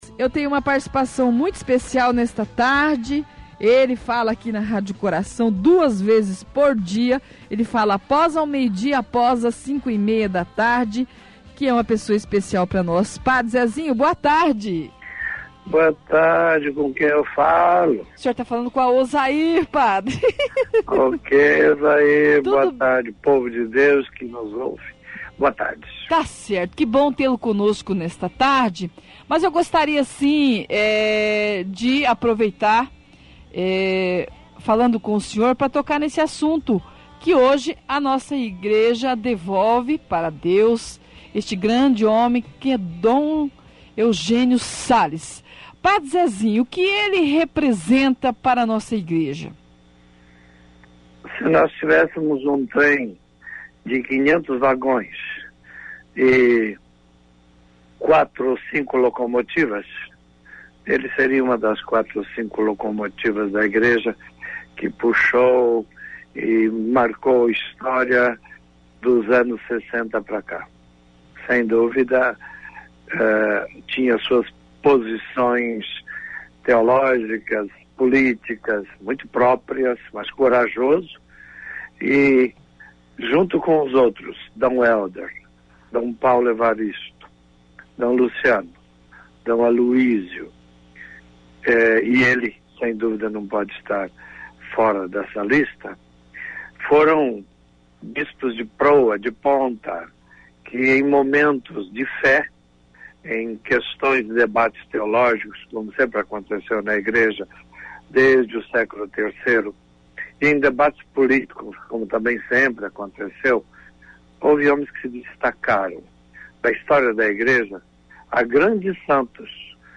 Ouça a integra da entrevista Entrevista Padre Zezinho na Rádio Coração 11-07-12.mp3 11M Seja o primeiro a comentar!